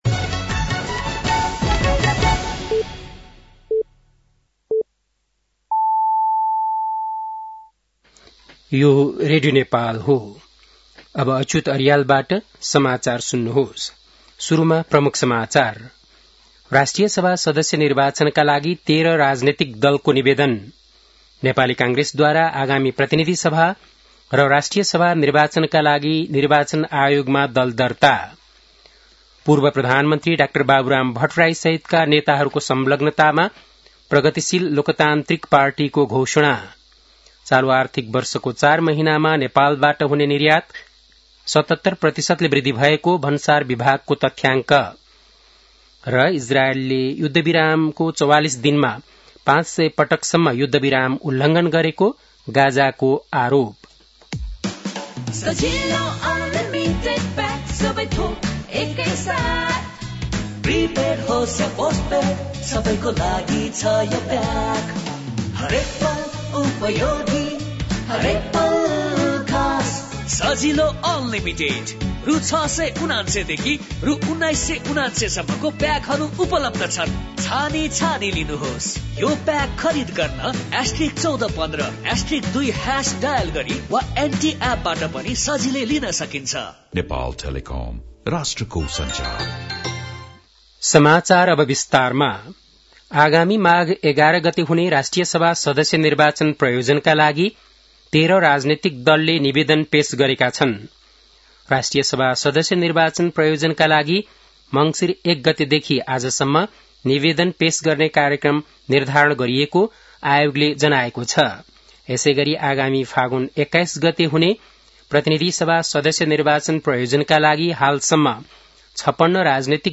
बेलुकी ७ बजेको नेपाली समाचार : ७ मंसिर , २०८२
7-pm-nepali-news-8-7.mp3